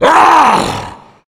burer_attack_0.ogg